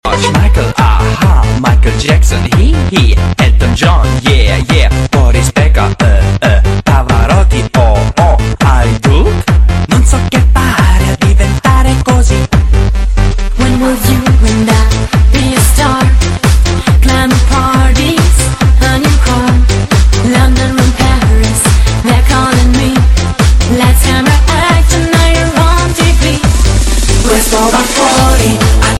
分类: DJ铃声
DJ舞曲